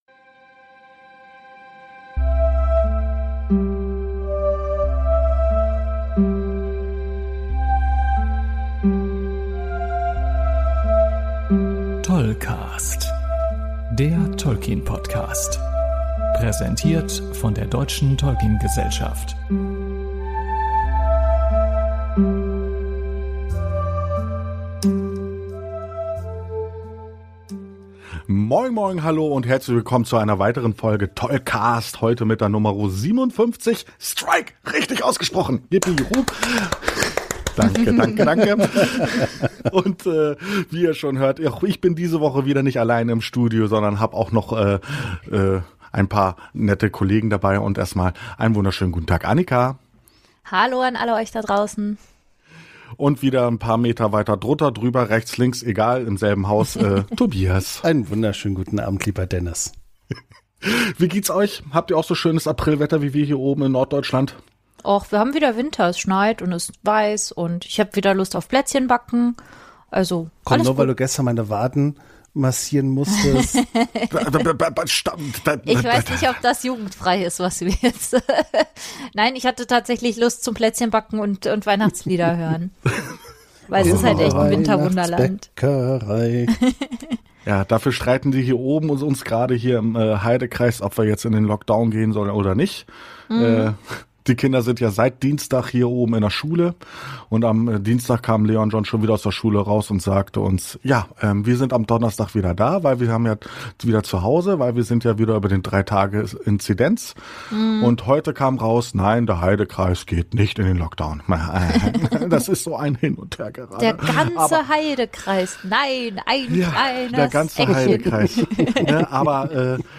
Nach dem zwölften Horn haben wir aufgehört zu zählen.